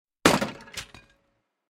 guard-crash.ogg.mp3